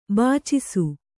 ♪ bācisu